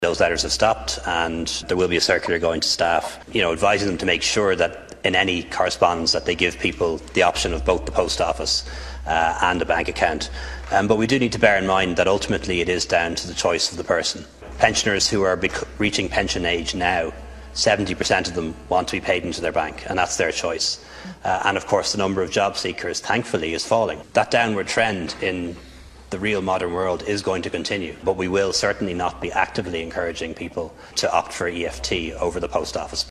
Responding to Deputy Michael Healy Rae in the Dail, Minister Leo Varadkar said the controversial letter would be replace with one outlines a clear choice for customers………..